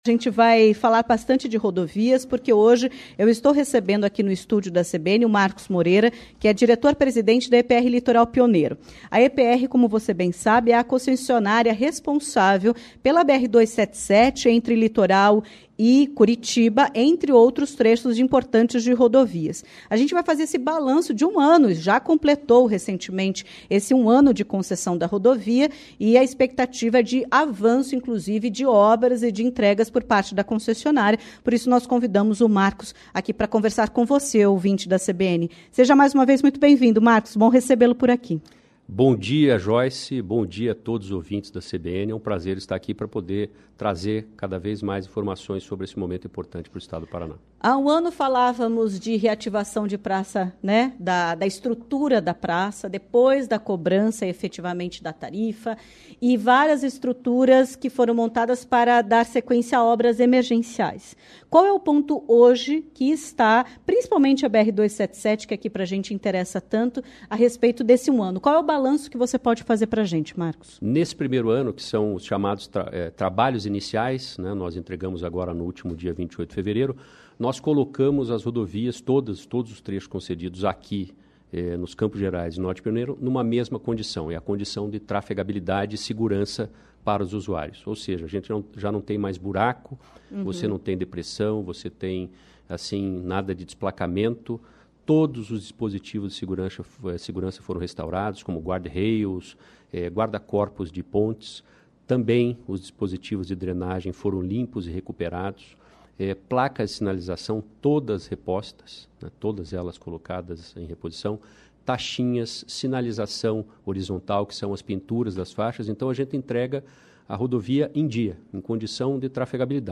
Em entrevista à CBN Curitiba nesta terça-feira (11)